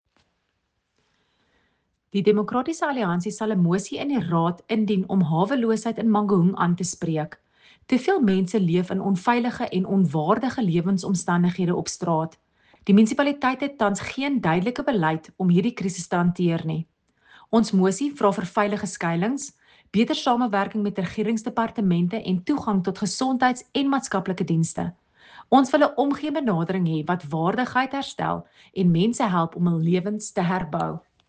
Afrikaans soundbites by Cllr Corize van Rensburg and